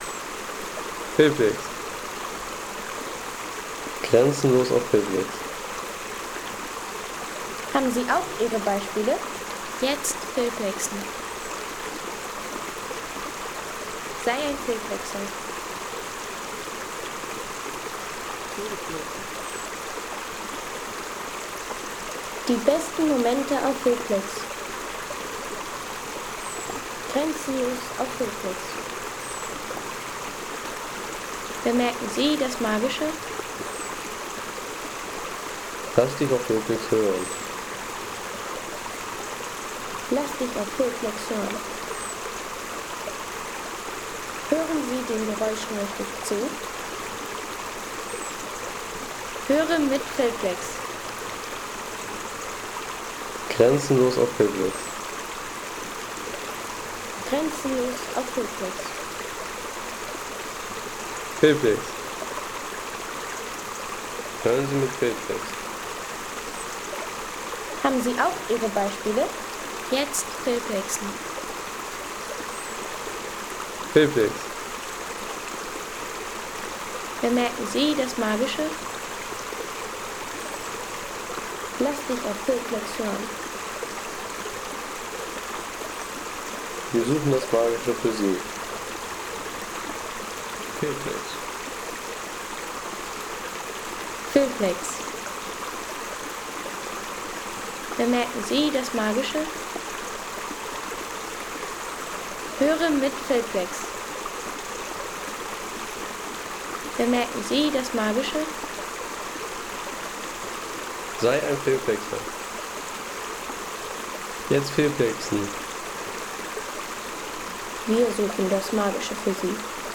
Wasserbach am Alpiner Wanderweg
Landschaft - Bäche/Seen
Alpiner Wasserbach am Stuibenfall – Ruhe im Wald.